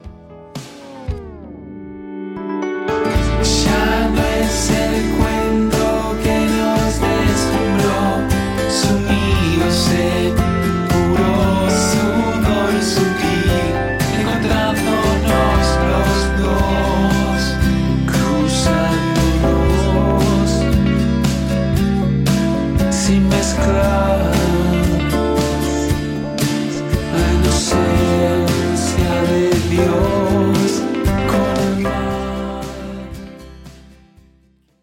Grabado en casa.
Guitarras